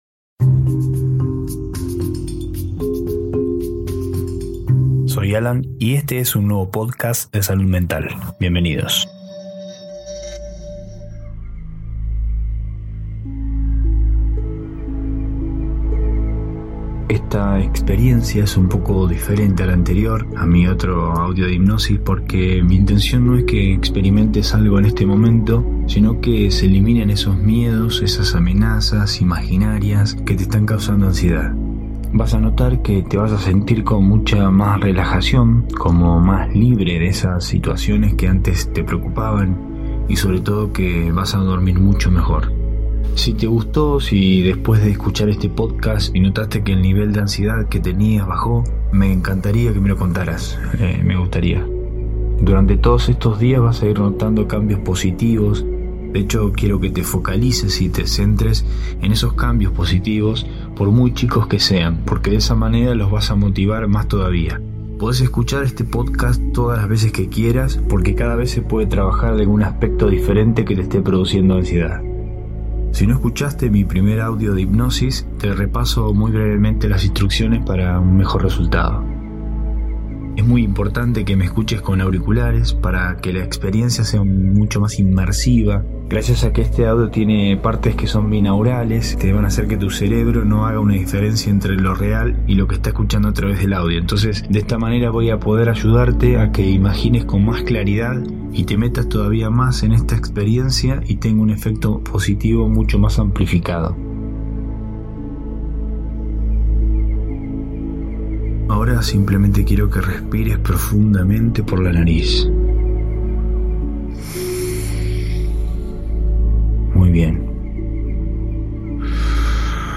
Sesión de autohipnosis guiada para trabajar la ansiedad, ataques de pánico y otras situaciones que te generan preocupación y angustia.